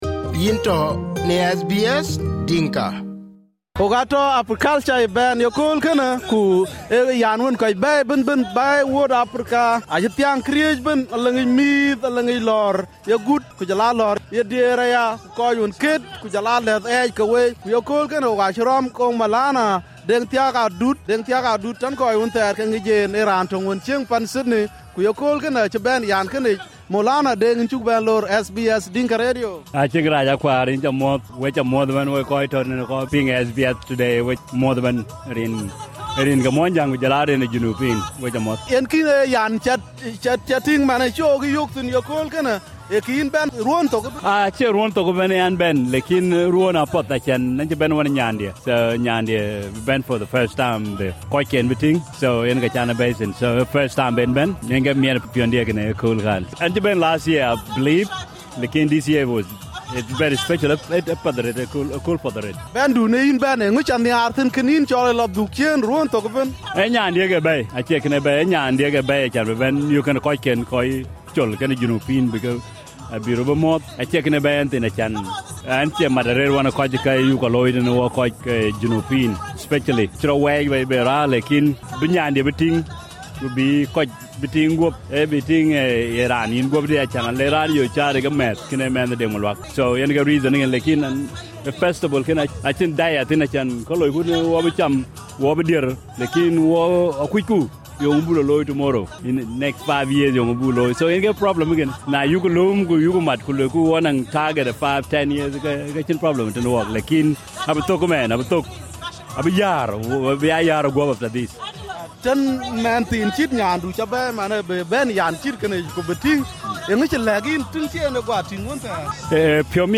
Africulture Festival Day in Sydney: Molana Deng Thiak Adut